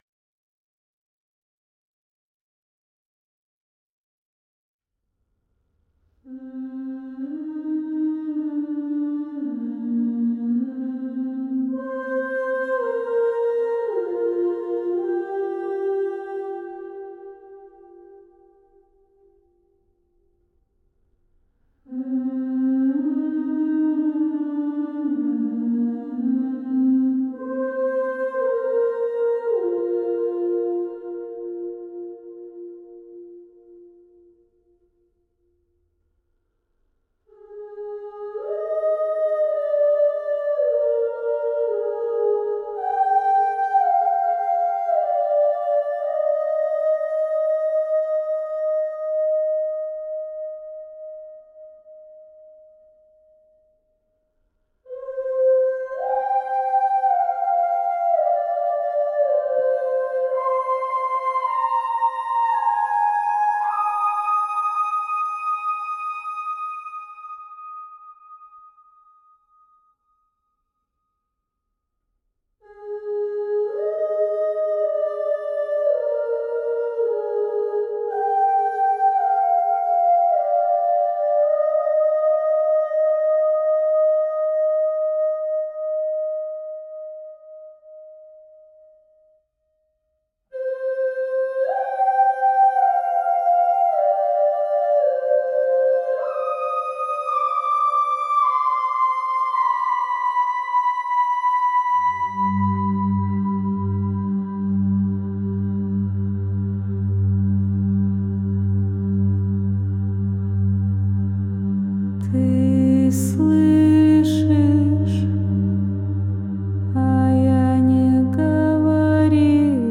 зеркальный хорал